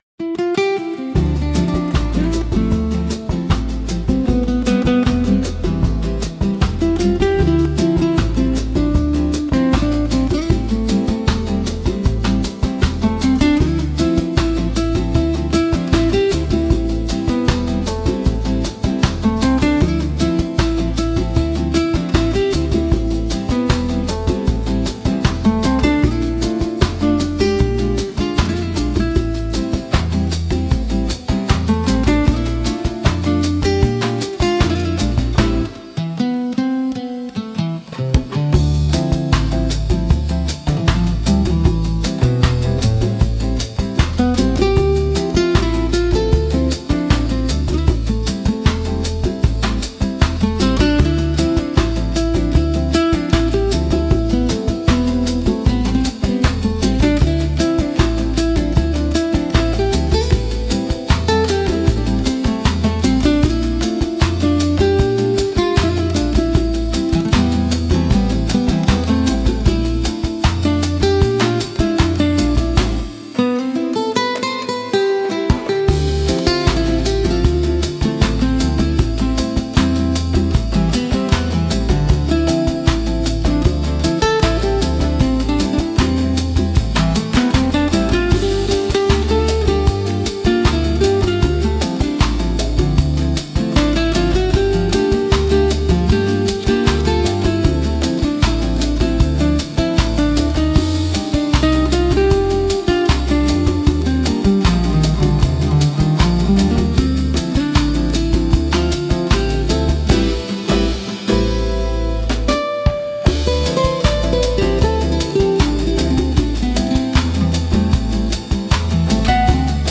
Chill Bossa Flow